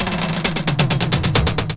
02_03_drumbreak.wav